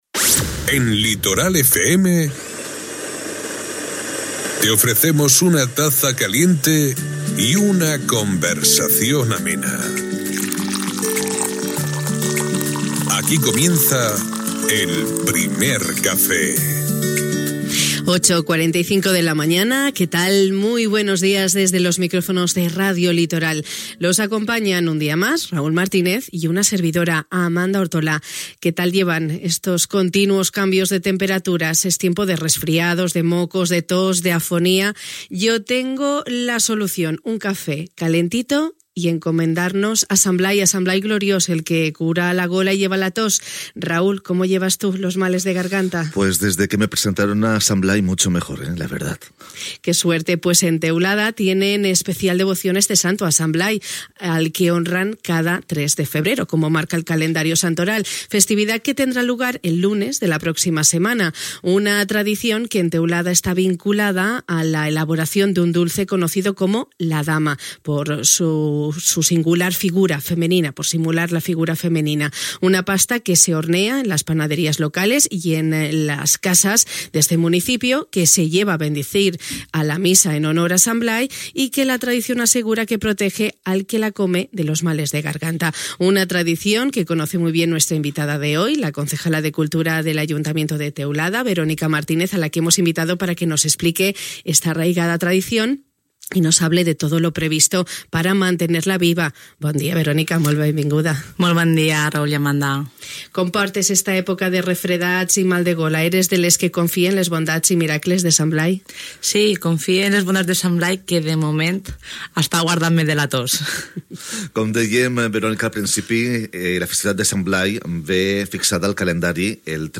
Ens ho ha explicat la regidora de Cultura, Verónica Martínez, amb la que hem compartit este temps de ràdio.